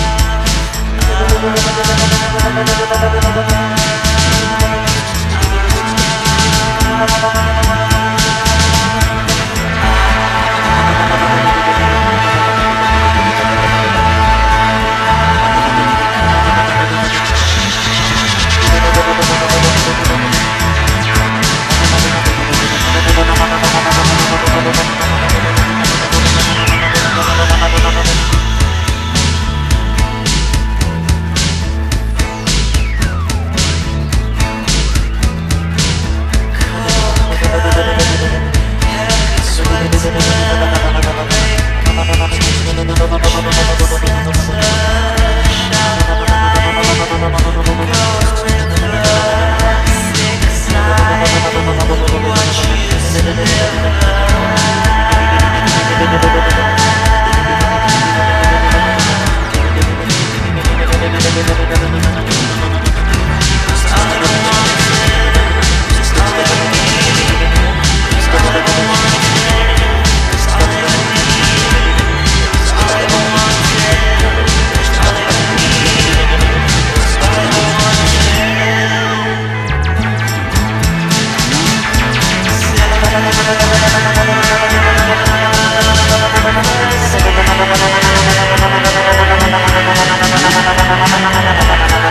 Ethereal Alternative Rock / Post Punk